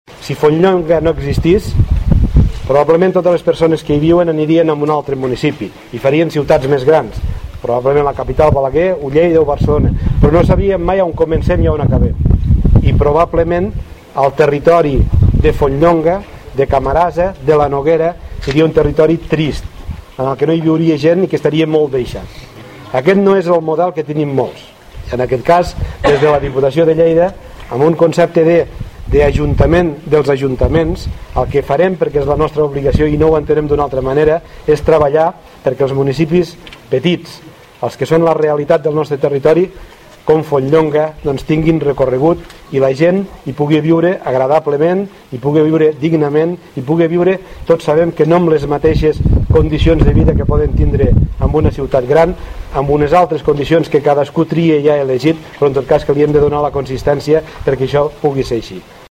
En el seu parlament, el president de la Diputació de Lleida ha fet referència a la composició de les comarques de Lleida, del Pirineu i l’Aran, formada, tal com ha dit, en bona part per municipis i pobles de menys de 1.000 habitants i ha destacat que una de les premisses prioritàries de la Diputació de Lleida per a aquesta legislatura serà la de treballar perquè els petits municipis i pobles tinguin recorregut.